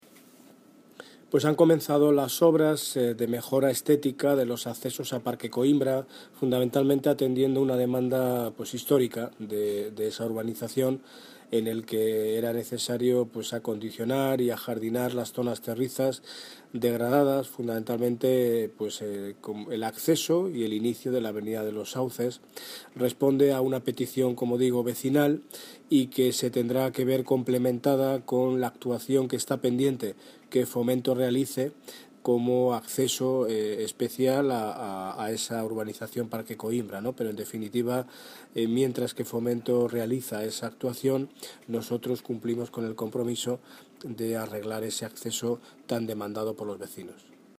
Audio - Daniel Ortiz (Alcalde de Móstoles) Acceso Coimbra